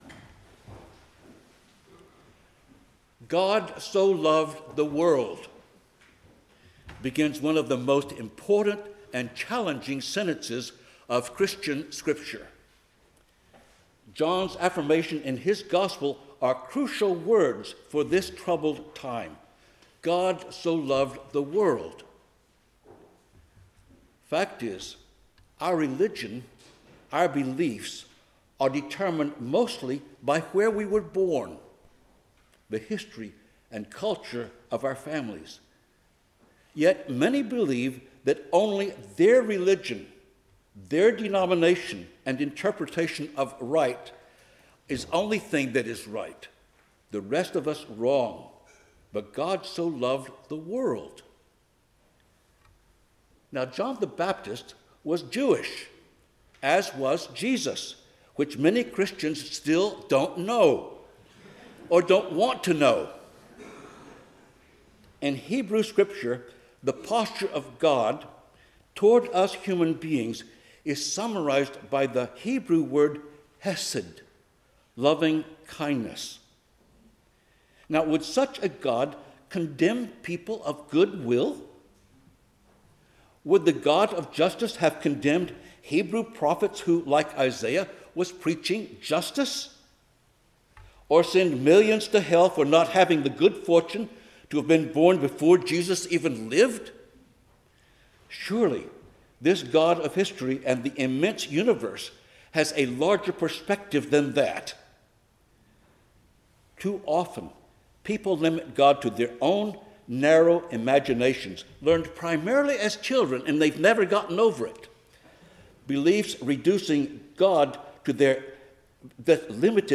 Service Type: 10:00 am Service